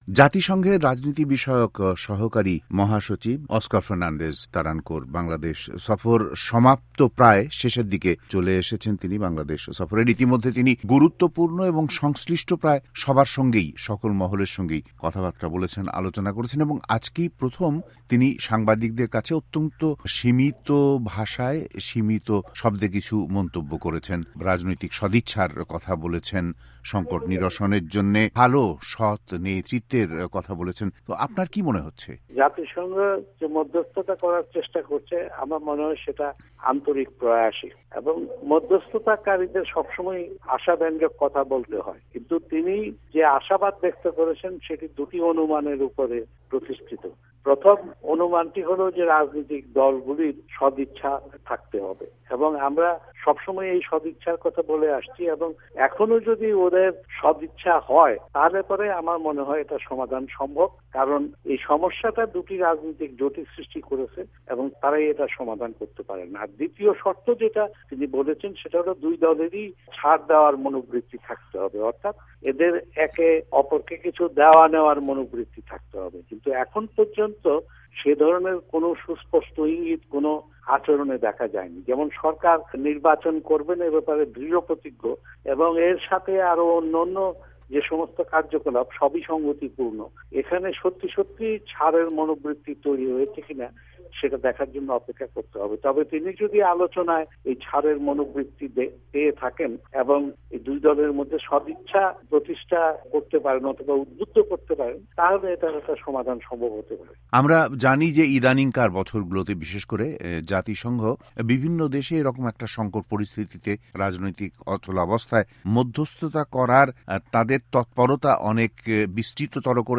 BANGLA POL INTERVIEW